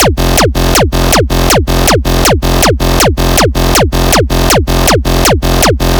frenchcore kick sample pack
This free Raw Frenchcore & Laser Kick Builder Pack gives you all the essential layers to build massive, distorted, festival-grade kicks tailored to your style.
• Add that aggressive, screechy snap to your attacks
🎧 BPM: 160 / 200
🎧 Perfect for Hardstyle, Rawstyle, Frenchcore & Hard Techno